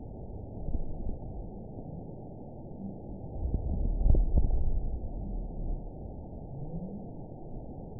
event 917876 date 04/19/23 time 11:12:18 GMT (2 years ago) score 9.36 location TSS-AB04 detected by nrw target species NRW annotations +NRW Spectrogram: Frequency (kHz) vs. Time (s) audio not available .wav